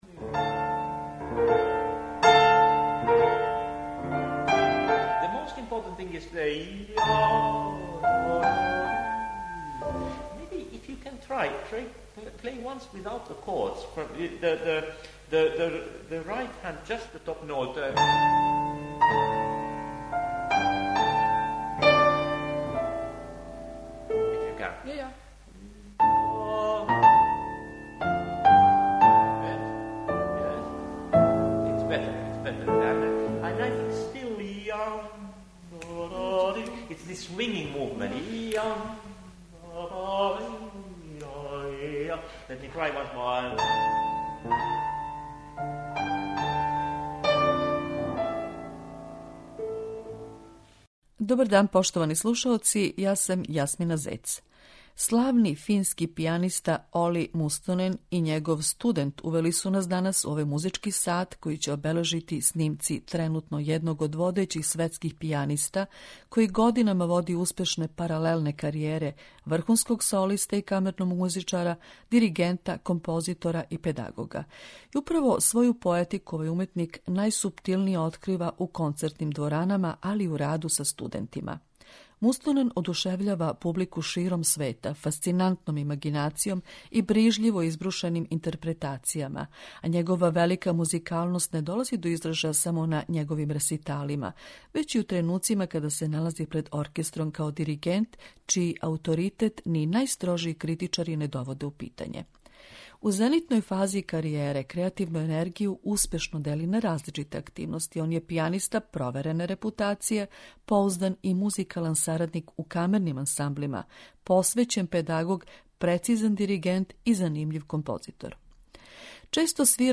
пијаниста